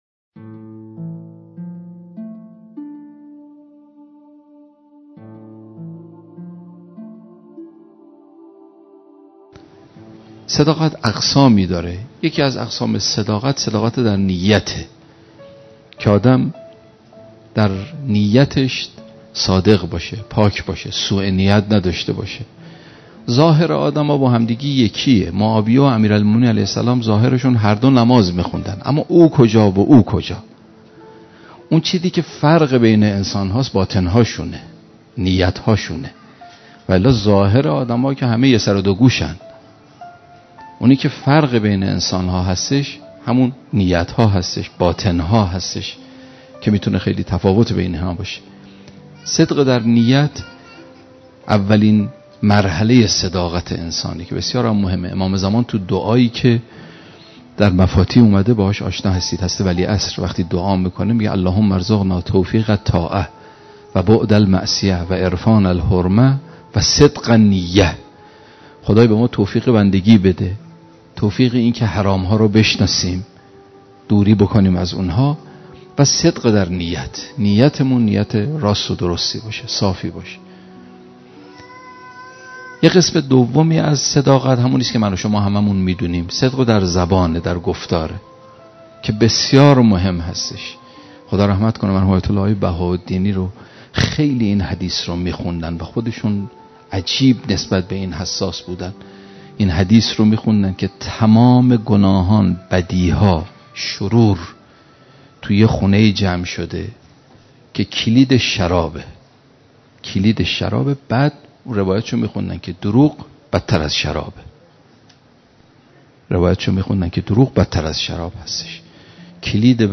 موضوع سخنرانی : صداقت
منبر مکتوب و آنلاین